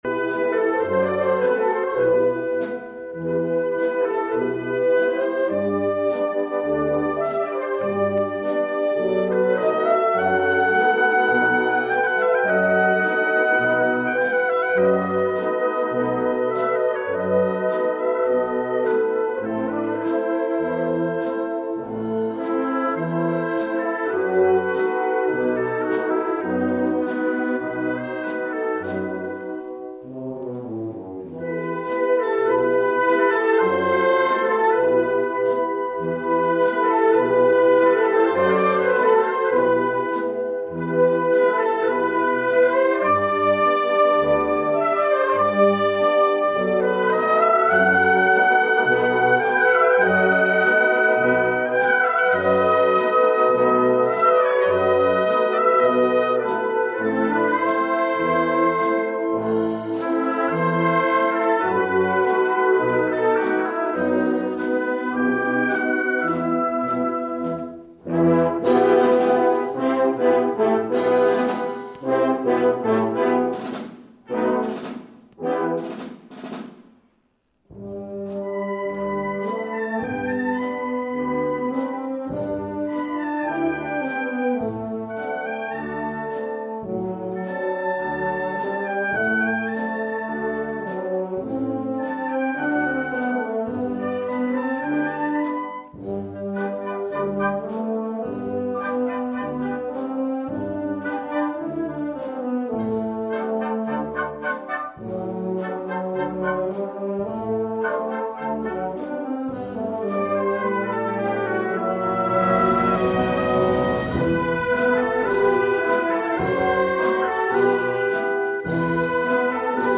La Agrupación Musical Sauces fuela encargada de interpretar las cinco piezas finalistas en el acto celebrado en el centro cultural Ramón Alonso Luzzy
La Agrupación Musical Sauces fue la banda encargada de interpretar en directo las cinco piezas finalistas del certamen, que este año se abría internacionalmente, por primera vez.
Según las bases del concurso, las marchas debían tener las características de ritmo y expresión de la Fiesta de Semana Santa Cartagenera, y ser aptas para ser utilizadas en los desfiles.